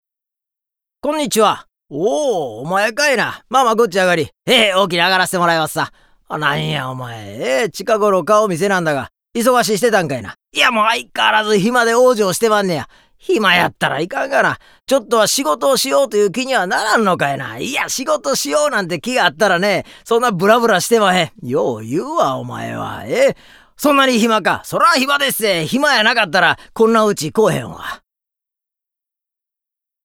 ボイスサンプル ＜上方落語＞
7_上方落語.mp3